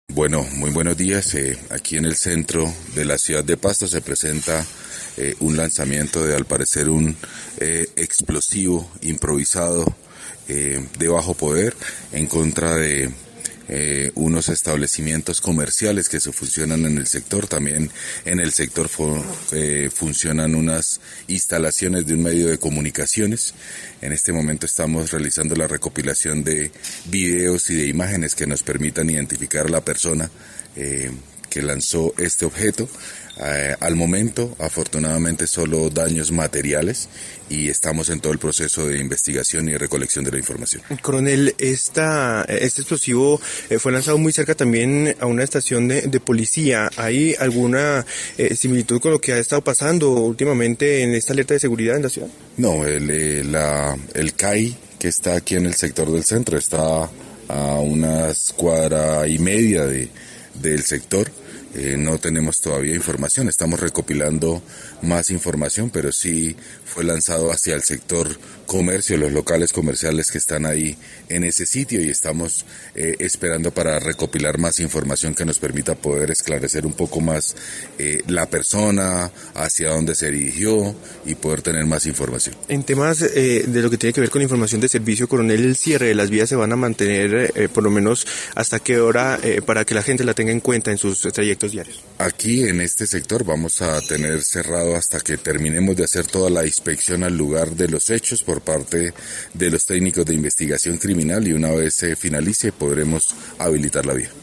En diálogo con Caracol Radio, el coronel Hernando Calderón, comandante de la Policía Metropolitana de Pasto informó que se está haciendo la recopilación de videos e imágenes que permitan identificar a la persona que lanzó este objeto.